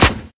uziShoot.ogg